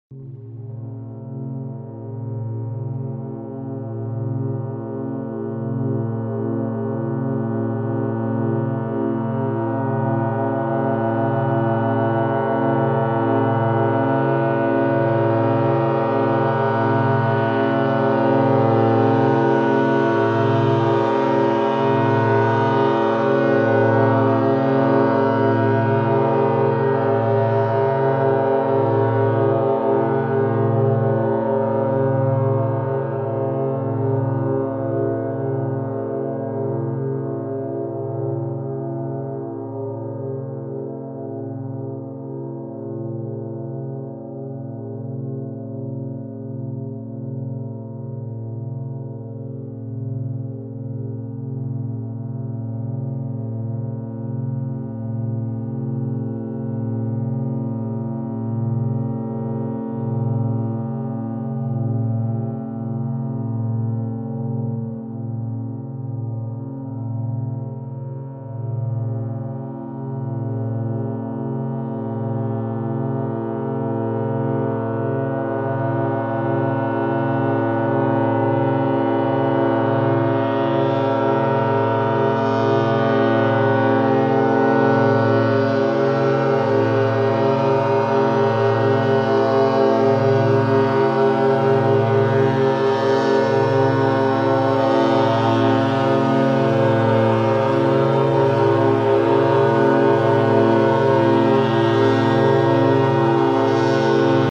Reine 852 Hz Frequenz mit sphärischer Ambient-Untermalung